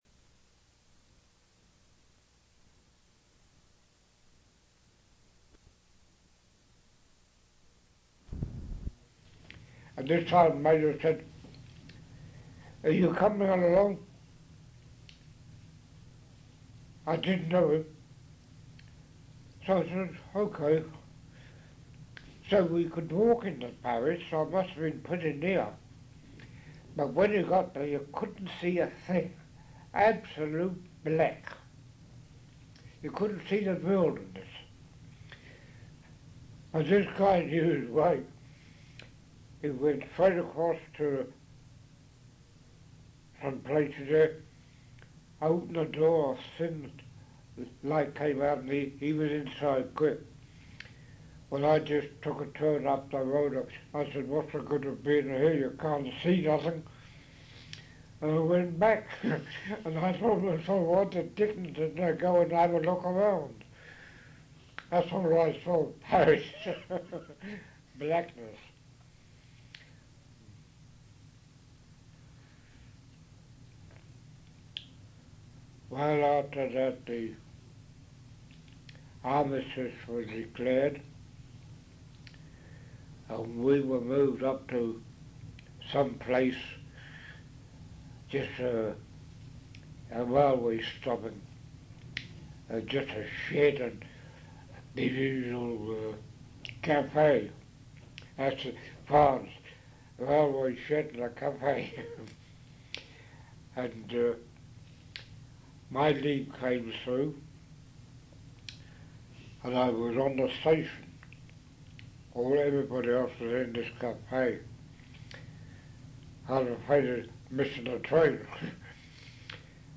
In Collection: Canadian Military Oral Histories
Speech is slurred on the recording.